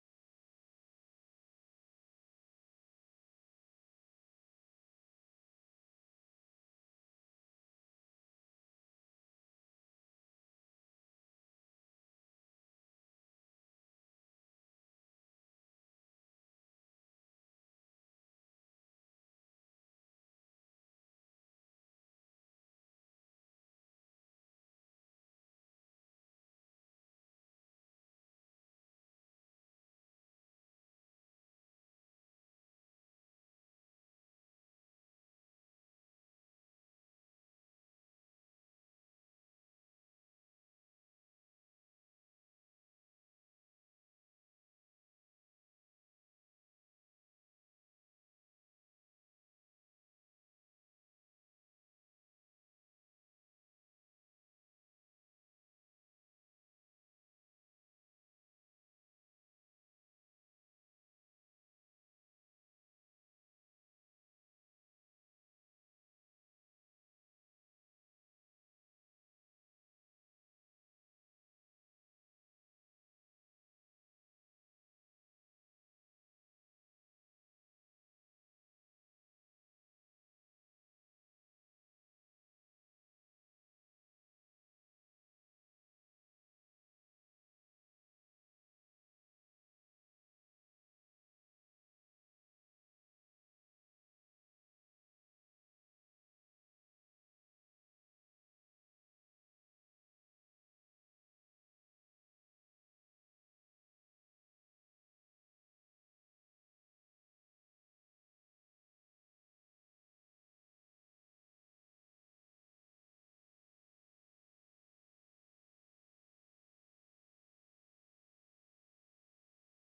July 23 2023 Sunday Worship
Our talented worship team brings the A game this week.
Praise Worship